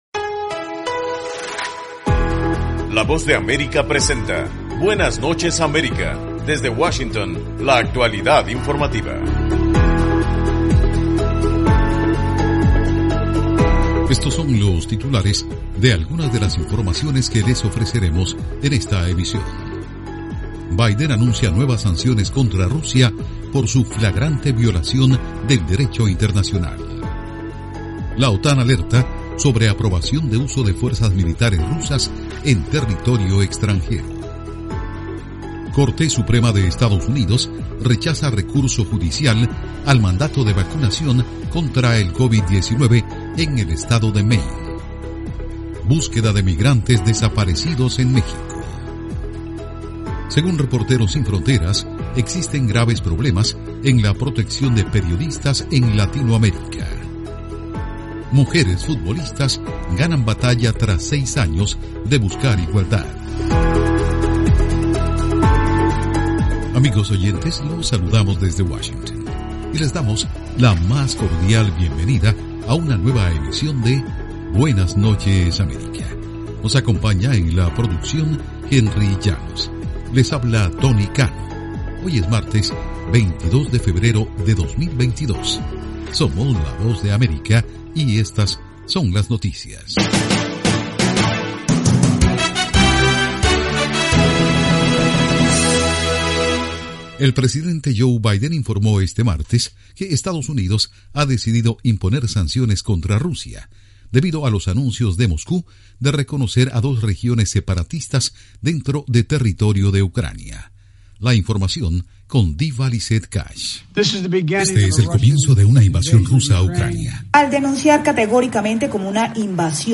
Programa informativo de la Voz de América, Buenas Noches América.